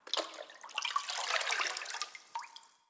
SFX_GettingWater_02_Reverb.wav